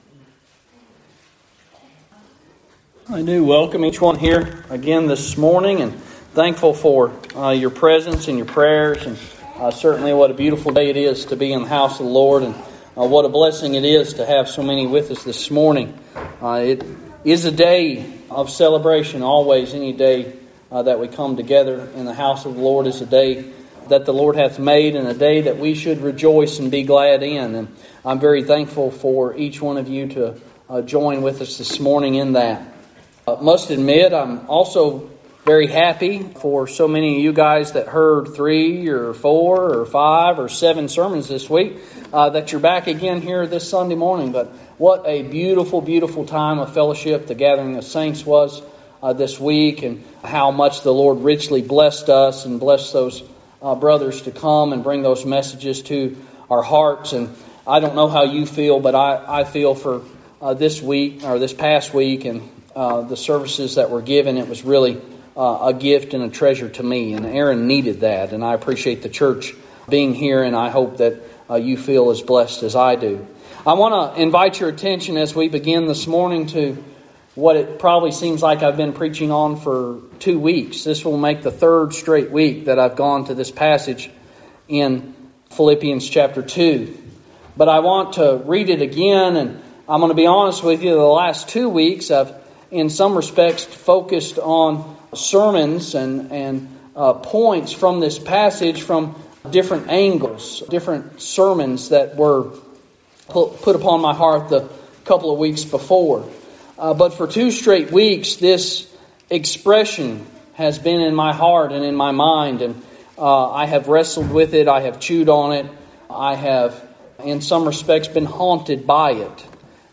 Even the Death of the Cross Apr 11 In: Sermon by Speaker